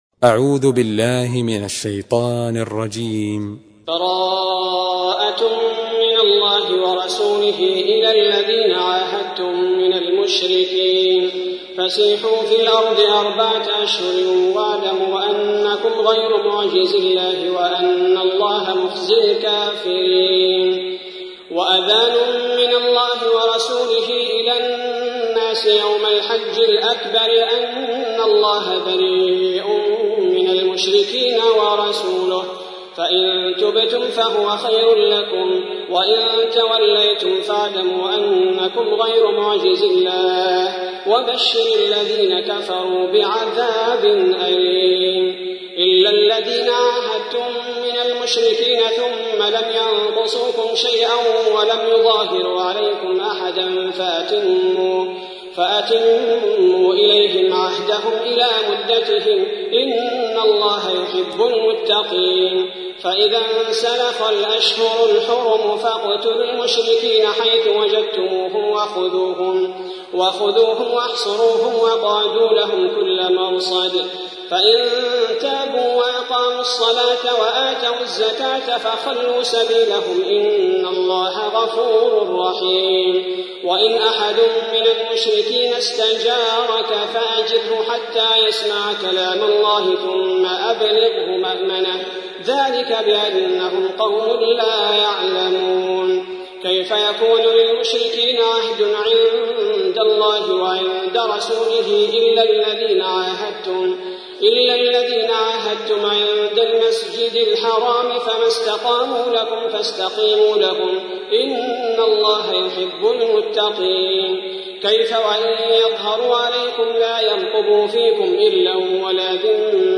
تحميل : 9. سورة التوبة / القارئ عبد البارئ الثبيتي / القرآن الكريم / موقع يا حسين